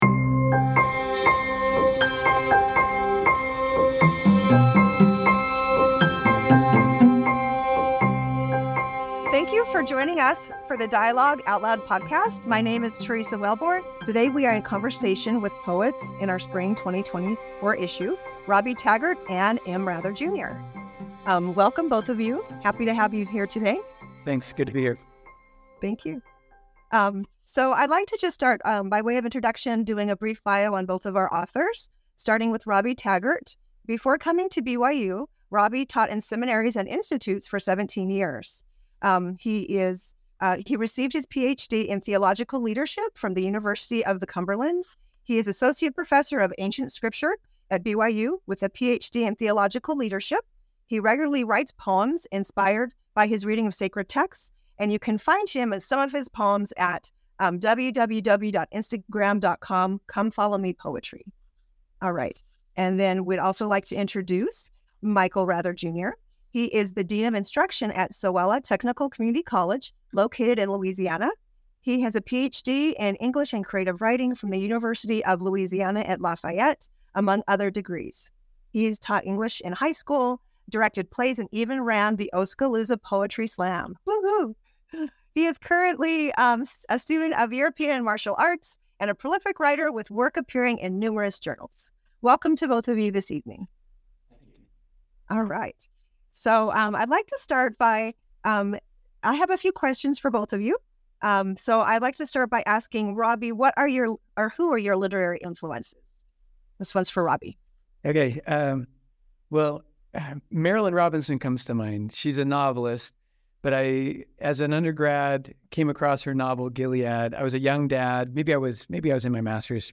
readings and discussions